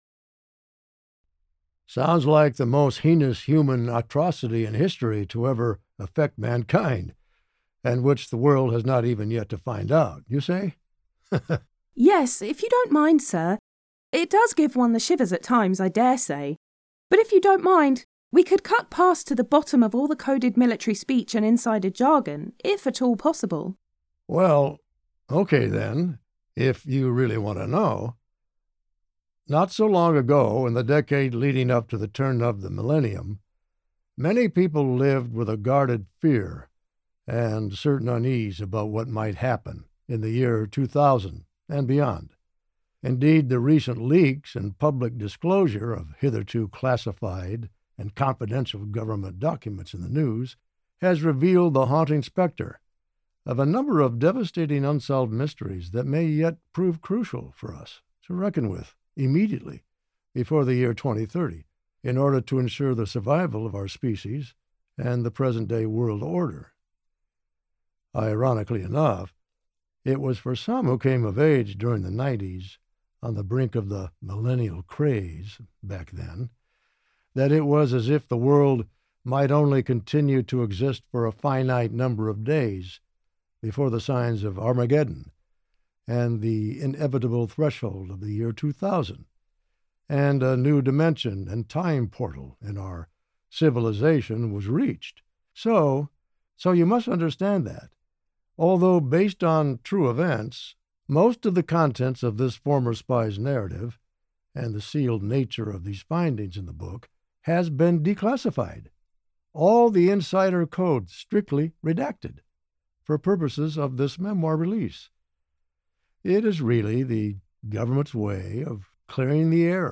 Listen to the Podcast Interview & TRUE Story Background Behind the Scenes